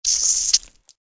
SA_fountain_pen.ogg